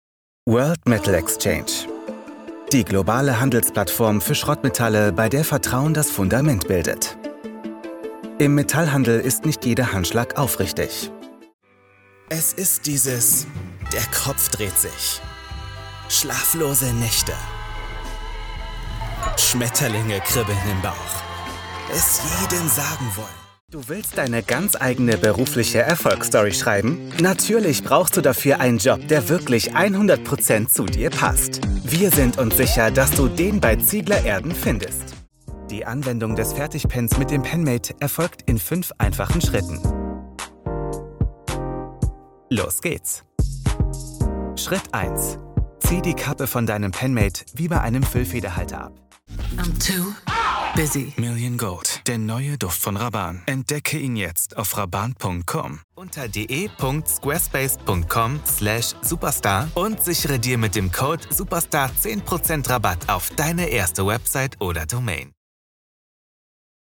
Radio Voice Imaging | Voice Over Talent for Radio Imaging | Radio Promos | Affordable Voice Talent
A clear, upbeat and engaging British voice that is particularly suited to professional business reads.